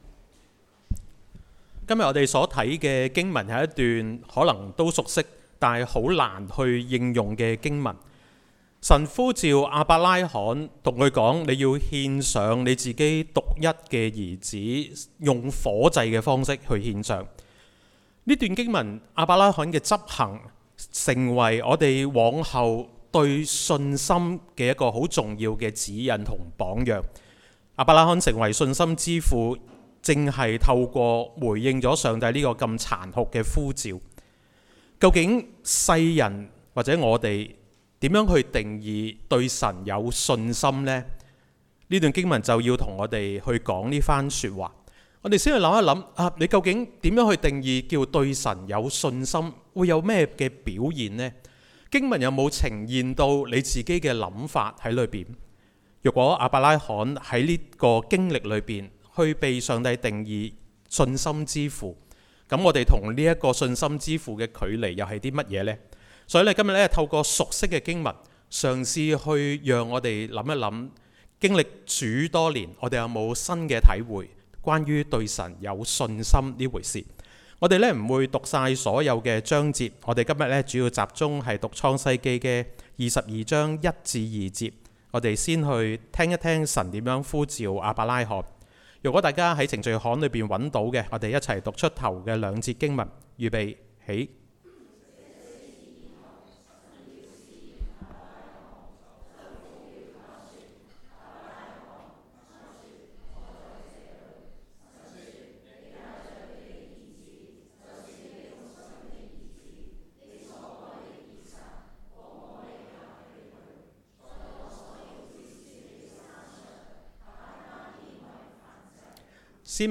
講道類別 : 主日崇拜 經文章節 : 創世記 22 : 1 - 14、希伯來書 11 : 17